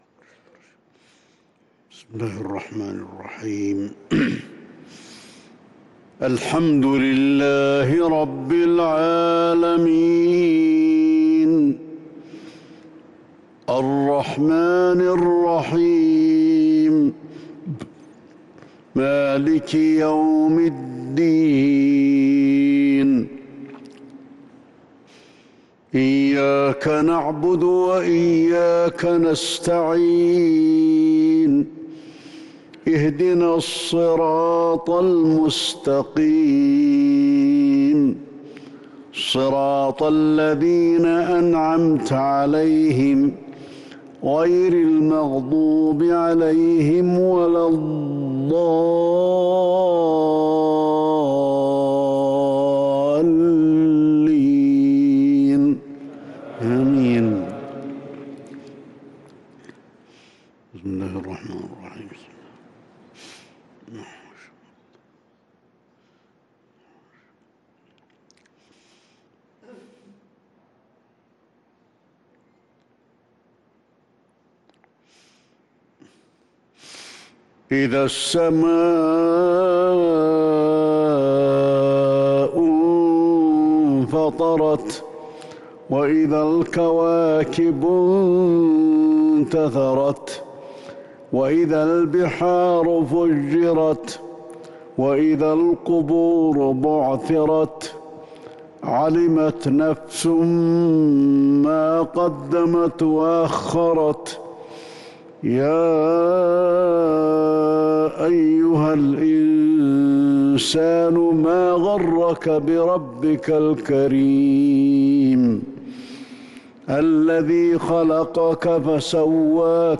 صلاة المغرب للقارئ علي الحذيفي 16 صفر 1443 هـ
تِلَاوَات الْحَرَمَيْن .